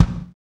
KIK XR.BDR01.wav